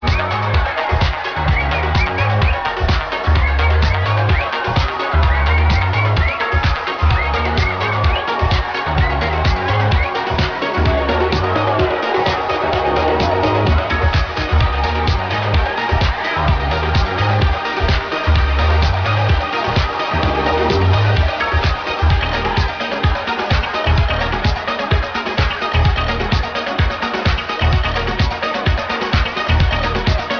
old-trance-tune